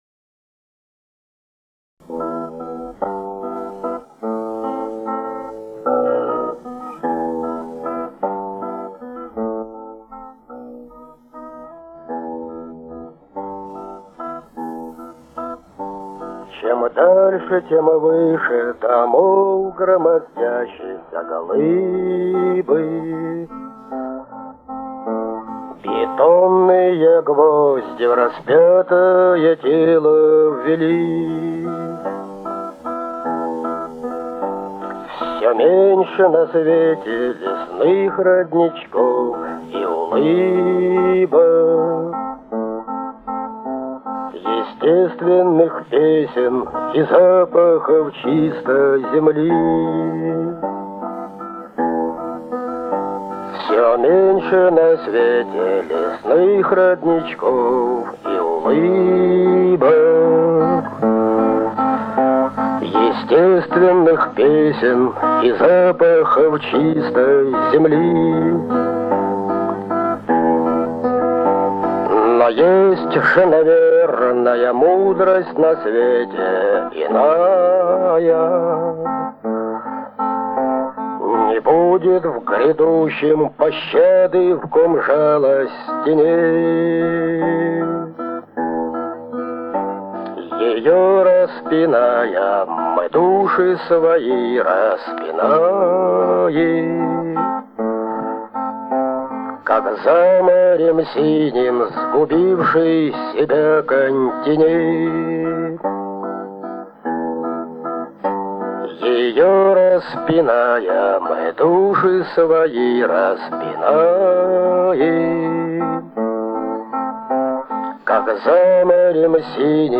«Чистый четверг» : песня.